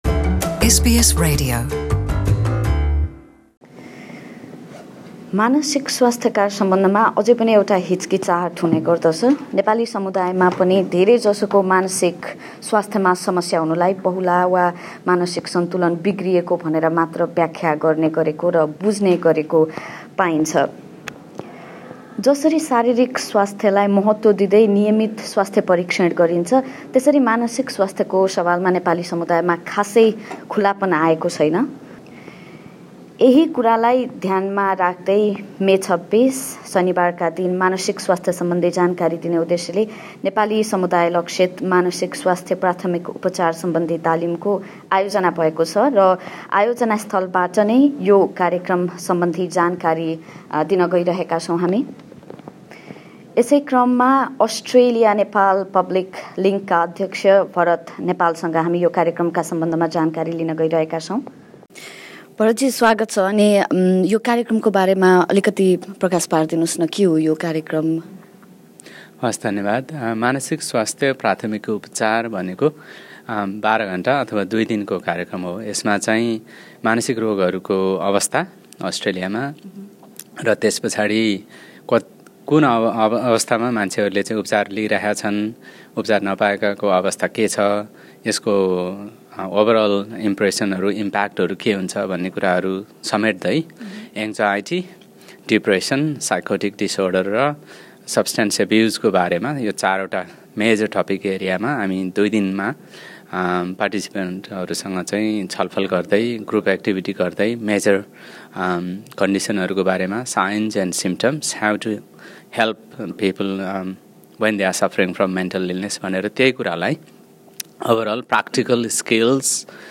कार्यक्रमबारे सहभागीहरु संगको पुरा कुराकानी सुन्नका लागि माथि रहेको मिडिया प्लेयरको प्ले बटन थिच्नुहोस्। मानसिक स्वास्थ्य बारे कुरा गर्न वा आफुलाई परेको समस्या बारे बताउँन मानिसहरु हिच्किचाउने कुरा नयाँ भने हैन।